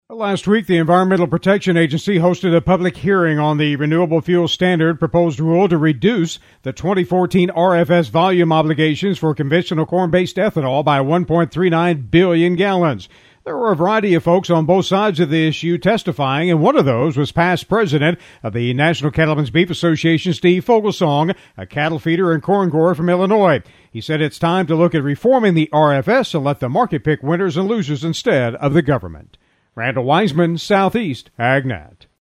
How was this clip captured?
A past president of the National Cattlemen’s Beef Association was one of thsoe who spoke at last week’s public hearing on the Renewable Fuel Standard.